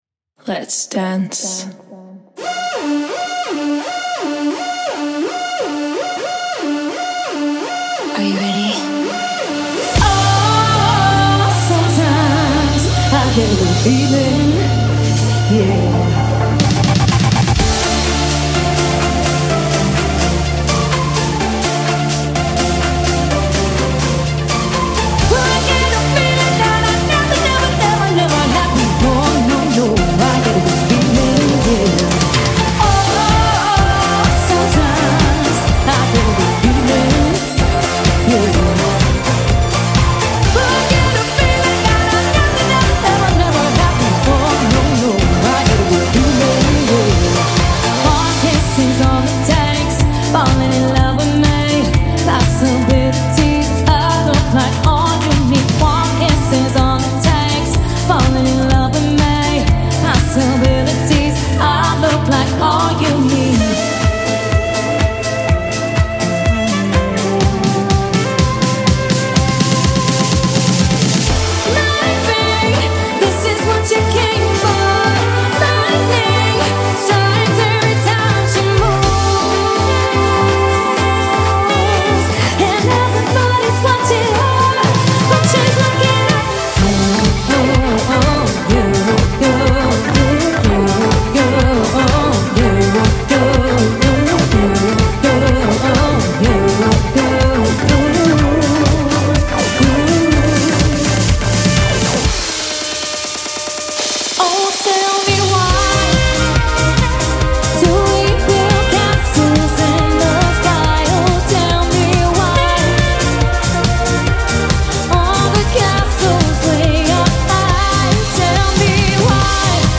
• 7-piece